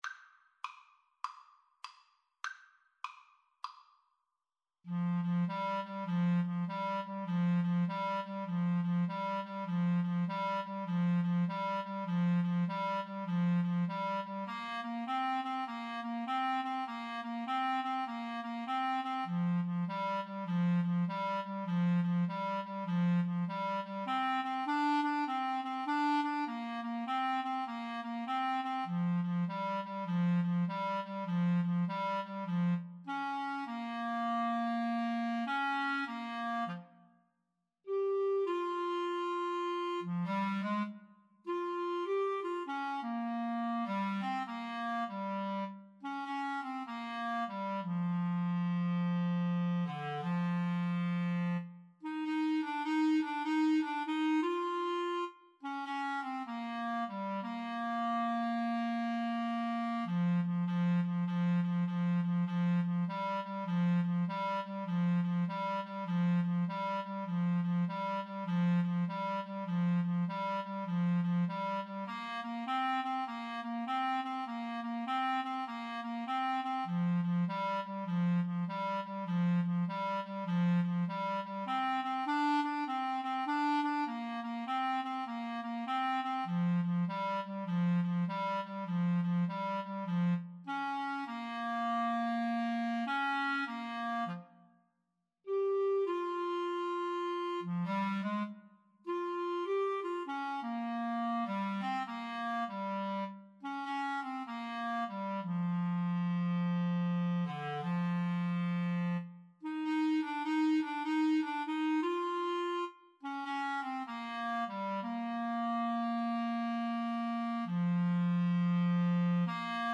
Play (or use space bar on your keyboard) Pause Music Playalong - Player 1 Accompaniment reset tempo print settings full screen
Bb major (Sounding Pitch) C major (Clarinet in Bb) (View more Bb major Music for Clarinet Duet )
4/4 (View more 4/4 Music)
Moderato
Clarinet Duet  (View more Easy Clarinet Duet Music)